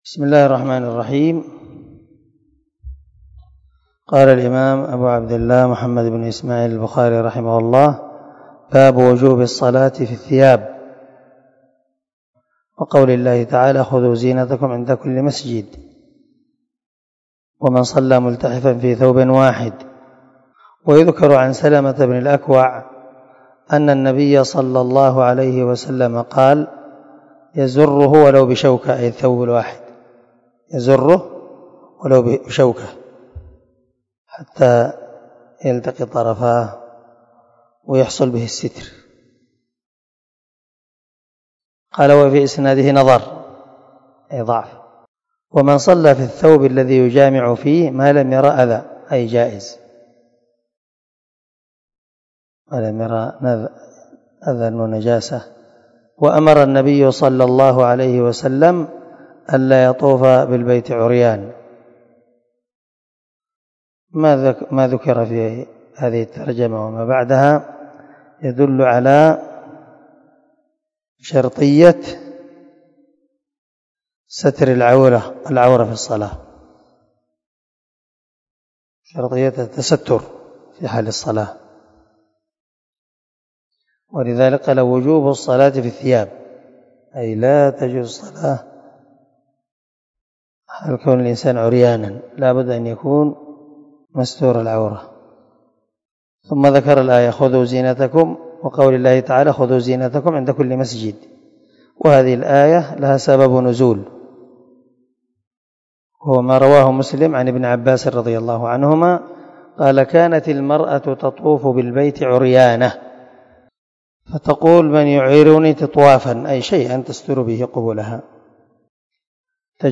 270الدرس 3 من شرح كتاب الصلاة حديث رقم ( 351 ) من صحيح البخاري